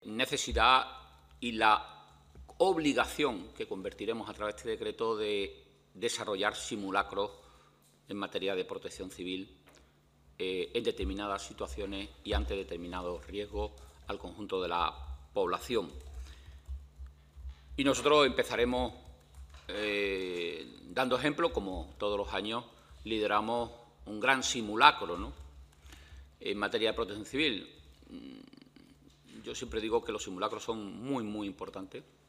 El consejero de la Presidencia, Interior, Diálogo Social y Simplificación Administrativa, Antonio Sanz, ha comparecido hoy a petición propia en la Comisión del Parlamento de Andalucía para analizar y explicar el Decreto de Autoprotección, que “verá la luz en 2026 y ha pasado el trámite de consulta pública previa sin alegaciones”.